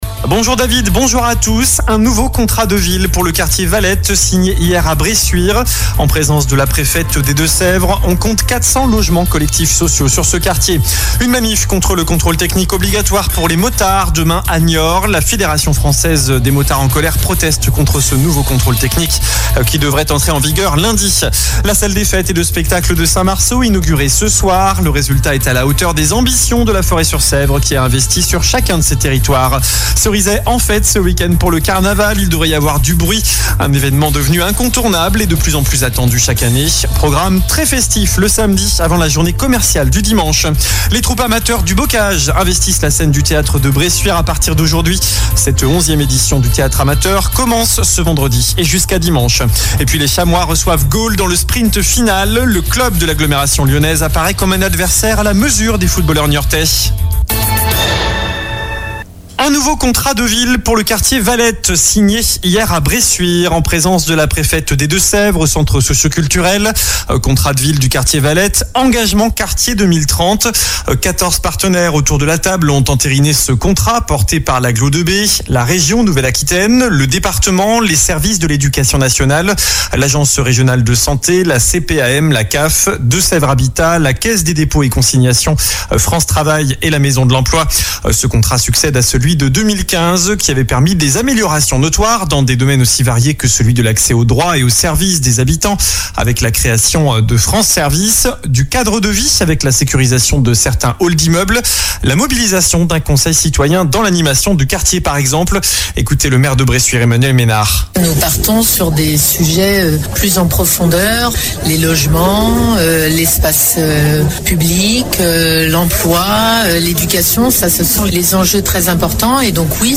Journal du vendredi 12 avril (midi)